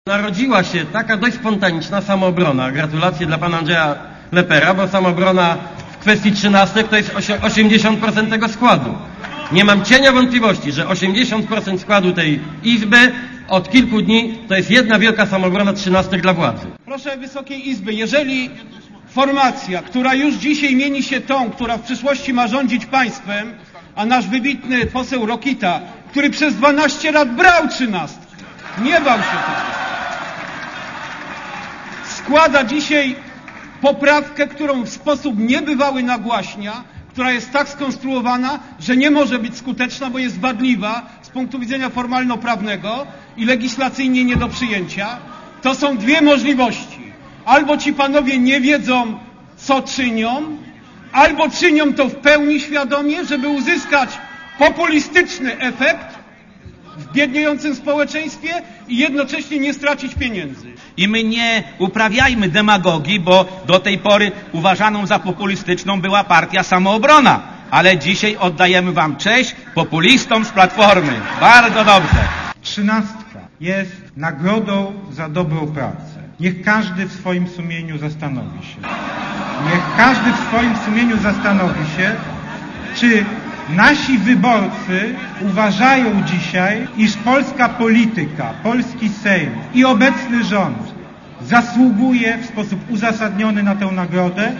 debata.mp3